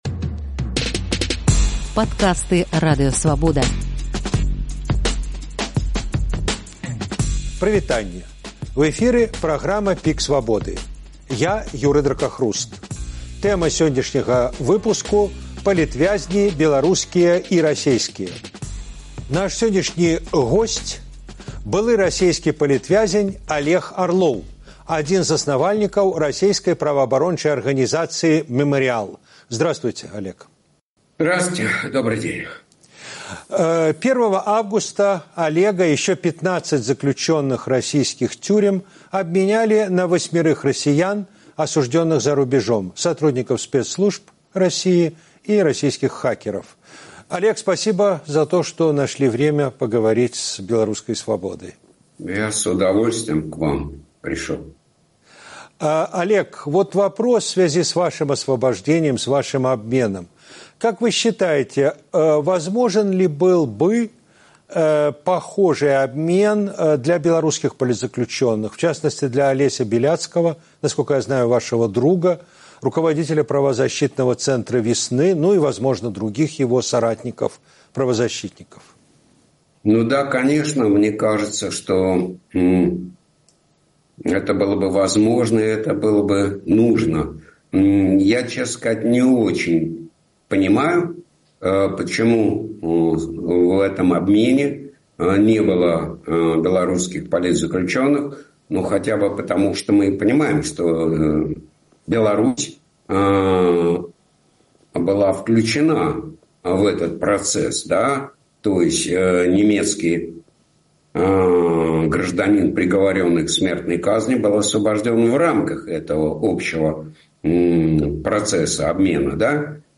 адказвае былы расейскі палітвязень Алег Арлоў, адзін з заснавальнікаў расейскай праваабарончай арганізацыі «Мемориал», вызвалены падчас нядаўняга вялікага абмену вязьнямі.